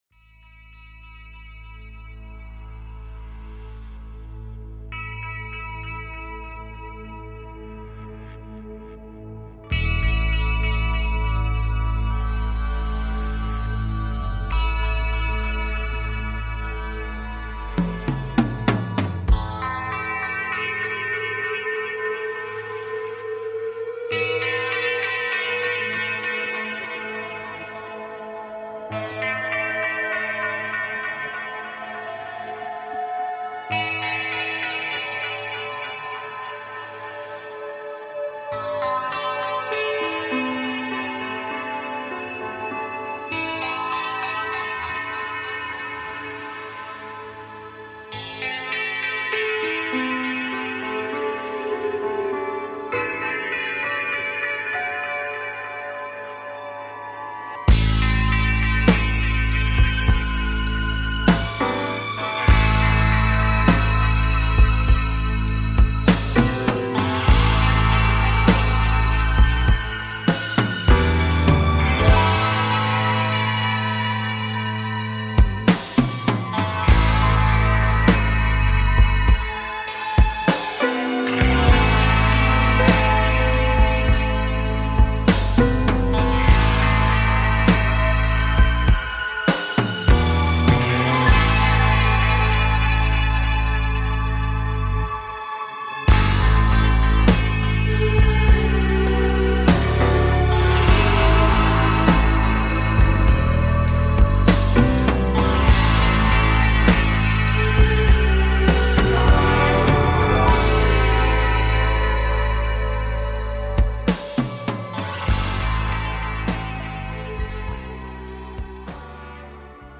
Talk Show Episode
Show on Homesteading and taking caller questions